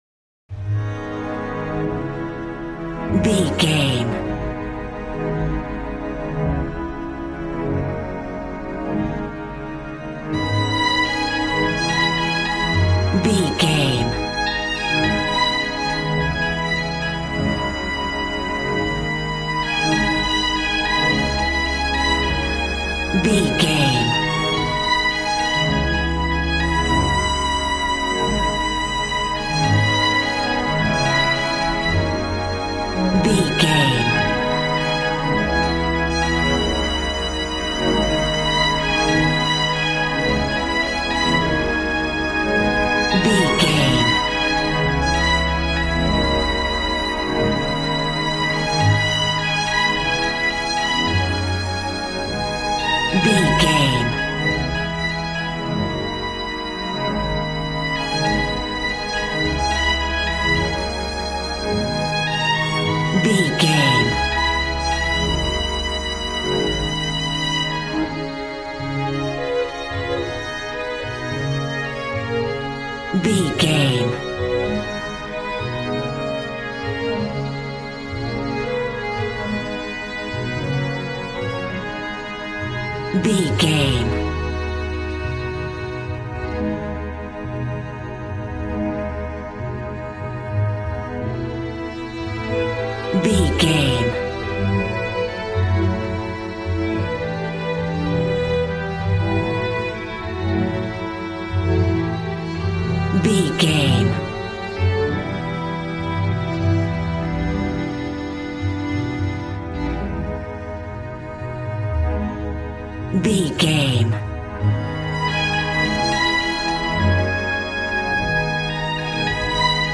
Aeolian/Minor
A♭
joyful
conga
80s